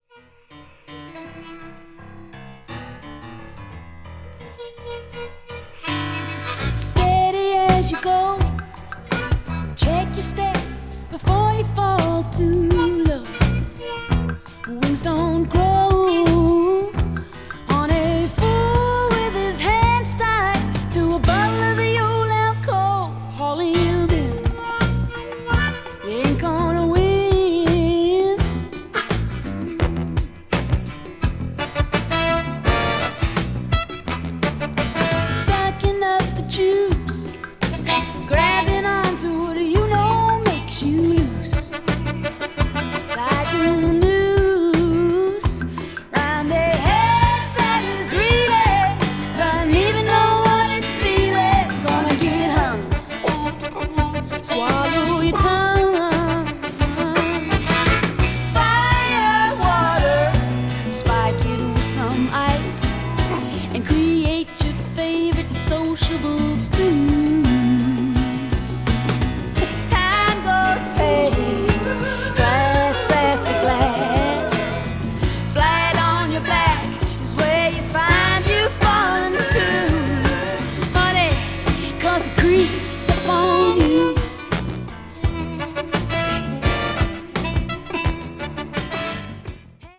media : EX/EX(some slightly noises.)
country   folk   pops   r&b   soul